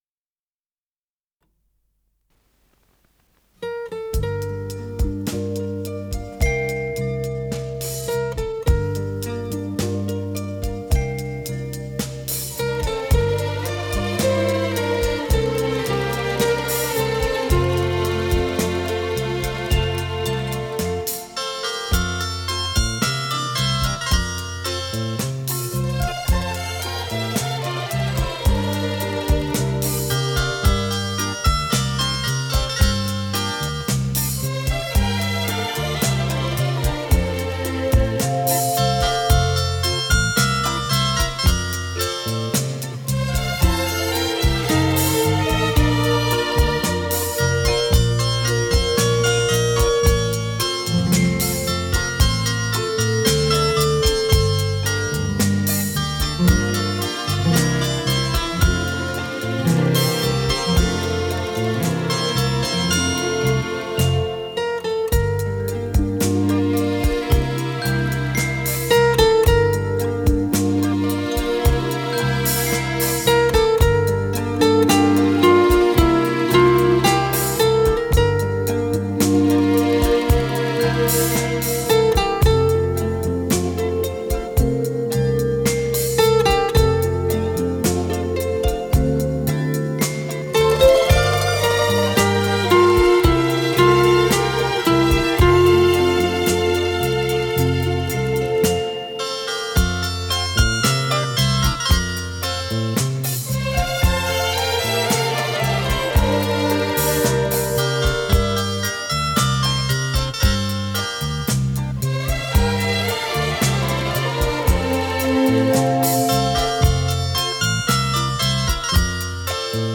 с профессиональной магнитной ленты
ПодзаголовокФа мажор
ВариантДубль моно